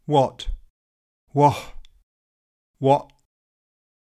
scouse what answer - Pronunciation Studio
scouse-what-answer.mp3